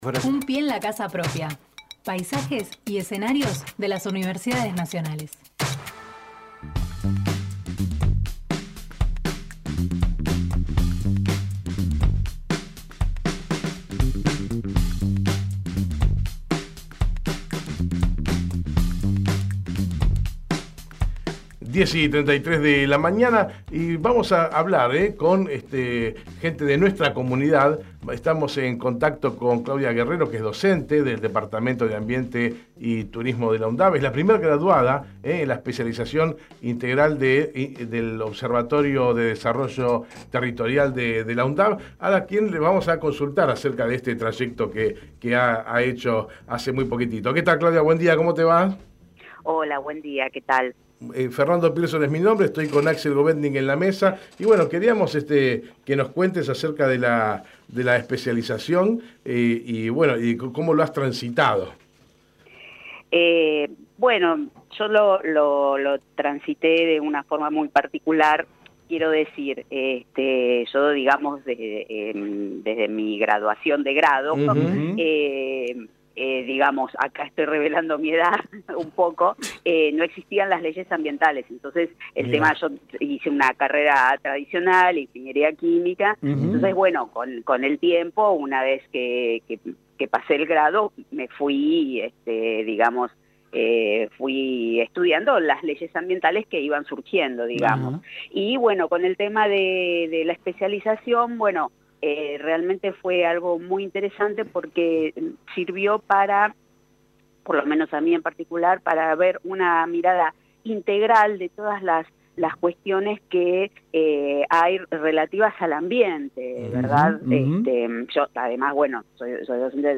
Compartimos la entrevista realizada en Hacemos PyE